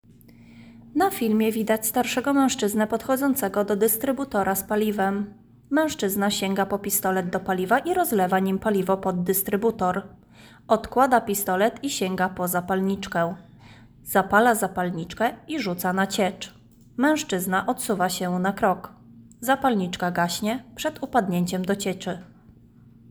Opis nagrania: Audiodeskrypcja do materiału nagranie z monitoringu